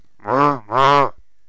bull_ack4.wav